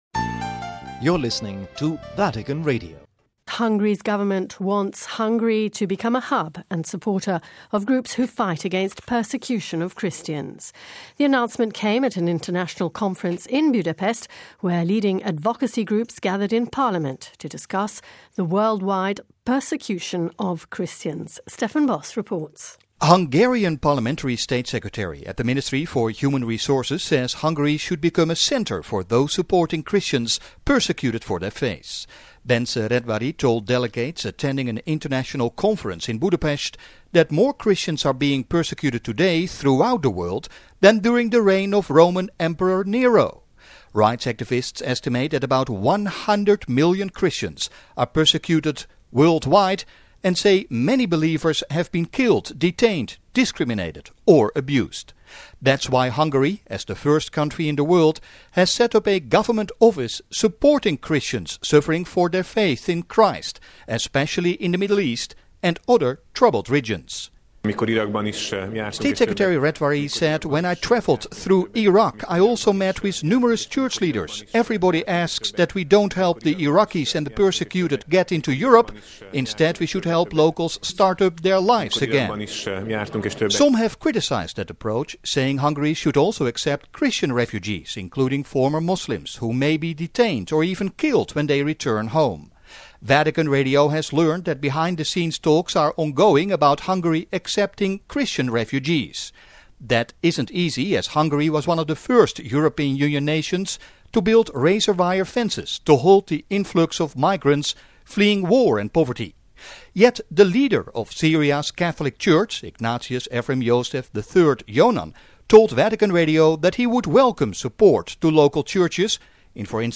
(Vatican Radio) Hungary's government says it wants Hungary to become "a hub and supporter" of groups who fight against persecution of Christians. The announcement came at an international conference in Budapest where leading advocacy groups gathered to discuss the worldwide persecution of Christians.